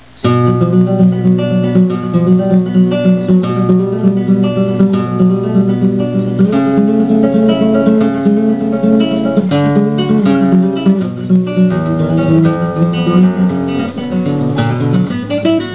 Tuning: EADGBE Key: D Major Sample: MIDI Format 1 (Better) | MIDI Format 2 |
This movement represents the impression Agustin Barrios had when leaving the calm, spiritual atmosphere of the Cathedral of San Jose and entering out into the street, where the hustle and bustle of the real world is represented by incessant 16th note arpeggio figures.